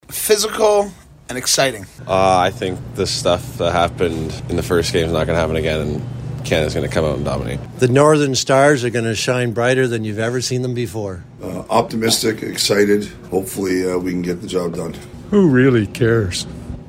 We hit the streets of Exeter-Grand Bend to get your thoughts.
can-us-streeters-3.mp3